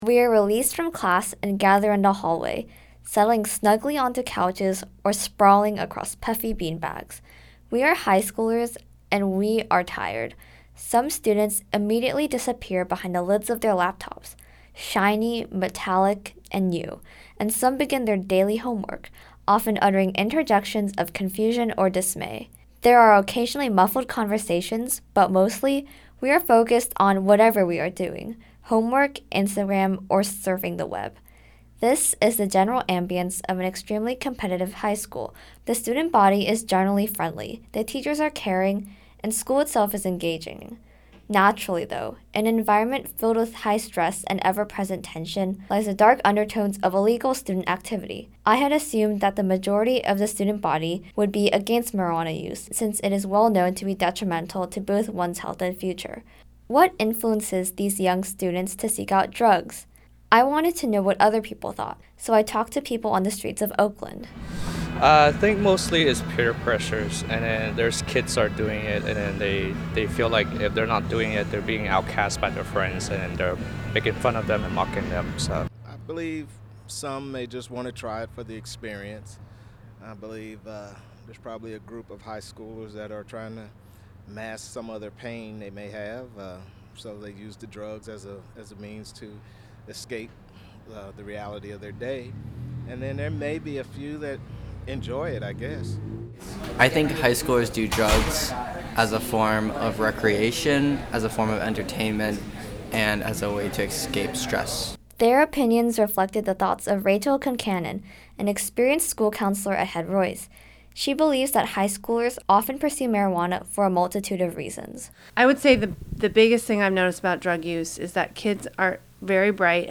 What are some underlying reasons for student drug use? A high school counselor and the people of Oakland give their thoughts and experiences.